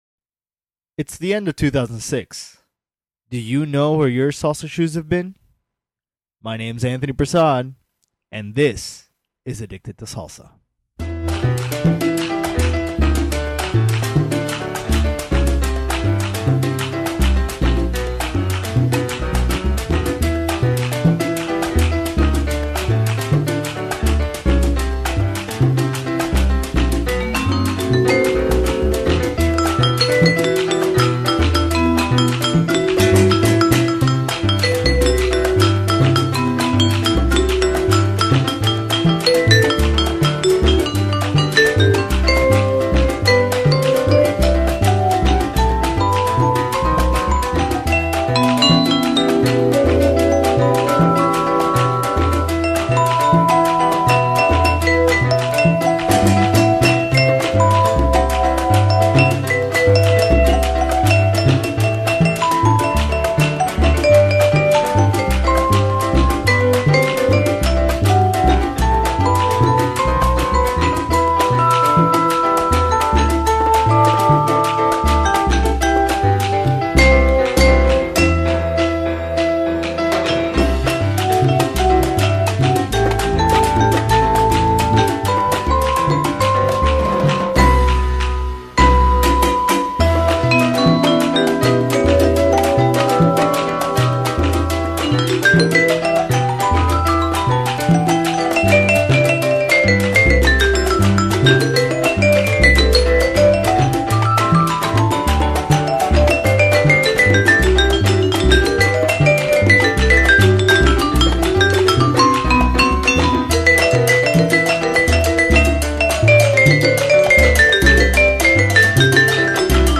Latin Dance Cover Salsa Songs - Addicted2Salsa
There are plenty of salsa songs built from classic Afro-Caribbean traditions, but there is also a long history of artists borrowing from pop, jazz, and other familiar styles to create Latin cover versions that still work on the dance floor.